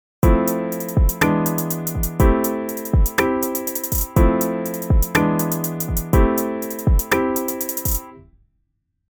前回のドラムパターンに続いて、今回は「コード」と呼ばれる伴奏パートをピアノの音色で作成します。
様々な楽器が選択できますが、今回は「Studio Instruments」から「Studio Piano」を選択し、「作成」をクリックします。
今回は、ポップス系の楽曲では定番のパターンの1つ、【Fメジャー7、E7、Aマイナー7、C】の4つのコードを使用していきます。
今回は、各コードを2分音符で入力したいと思います。
▶コードを追加した状態
AddPiano.mp3